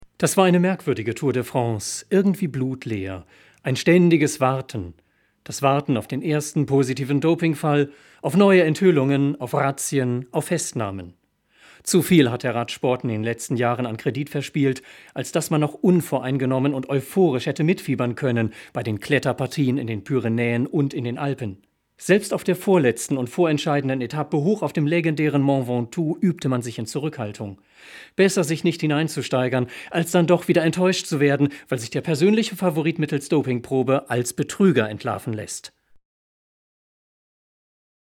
Deutscher Sprecher, Literaturlesungen, Kulturmoderation, Bariton
Sprechprobe: eLearning (Muttersprache):
Native German narrator for literature, readings, and presentation of cultural programmes